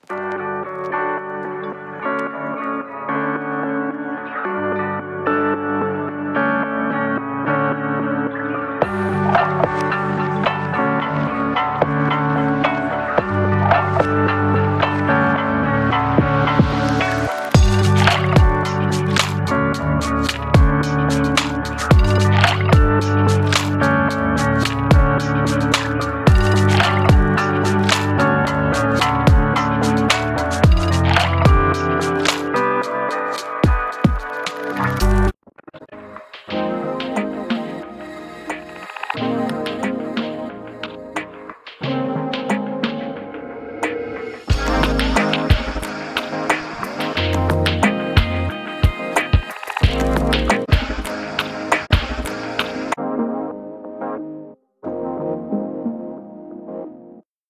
声音有一种梦幻和怀旧的感觉，它们是温暖和有机的，具有颗粒状的低保真质地。
在声音设计时，我们使用了模拟设备，因为它是最干净的音频信号源之一，再加上数字处理，它为我们提供了顶级行业质量的声音。
•环境
•冲击